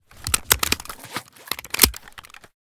vz61_reload.ogg